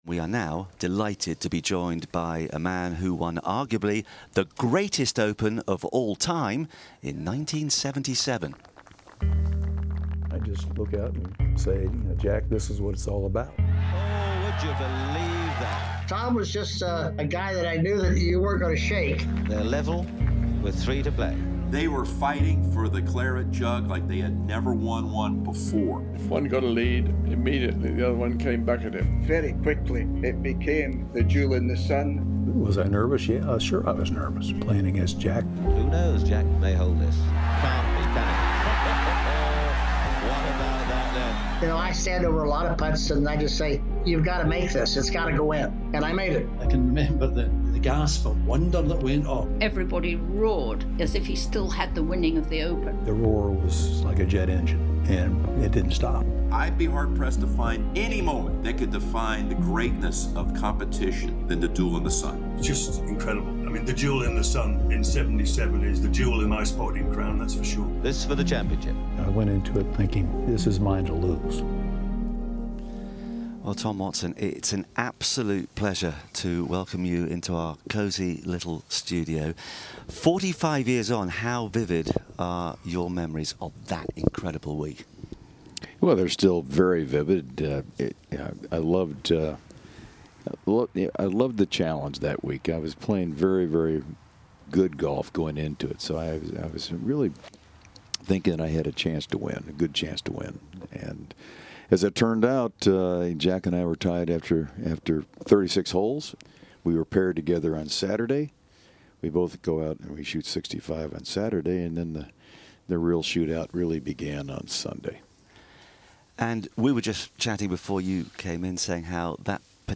Tom Watson discusses his career with The Open Radio team, during The 150th Open from St Andrews.